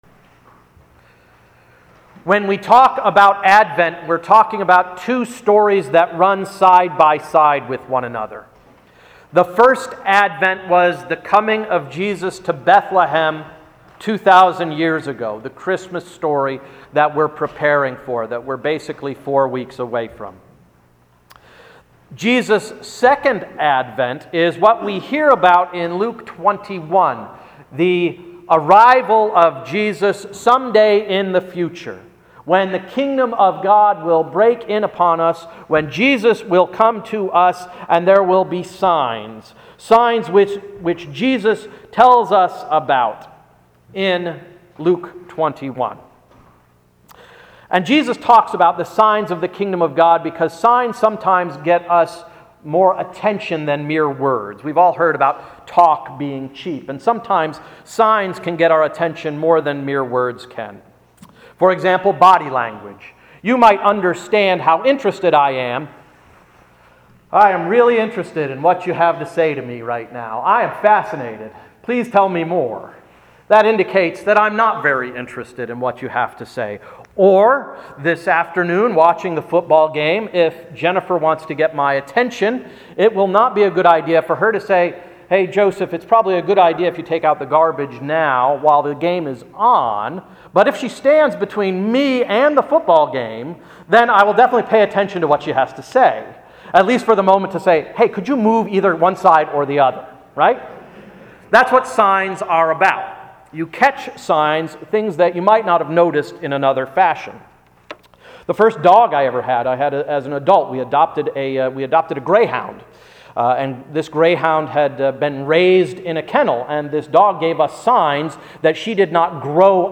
Sermon of December 2, 2012–“Sign Language”